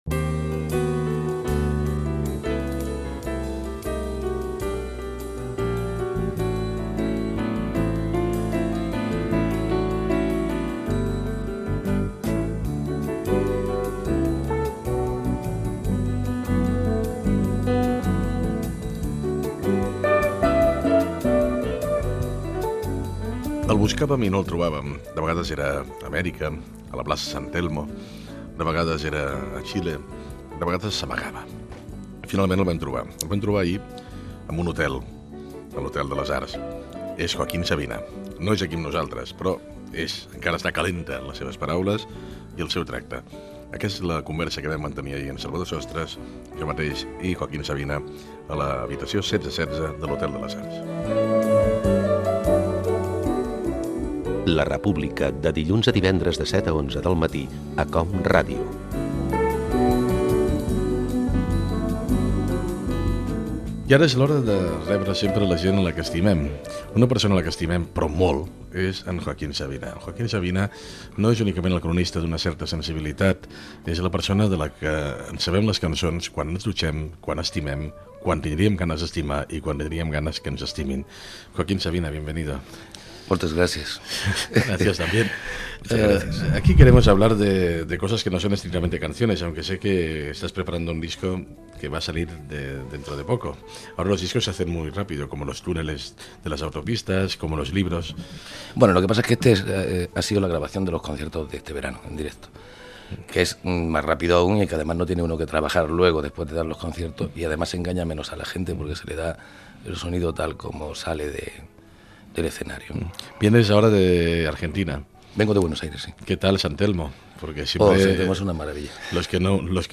Perfil biogràfic i fragment d'una entrevista al cantant Joaquín Sabina feta en un hotel de Barcelona
Info-entreteniment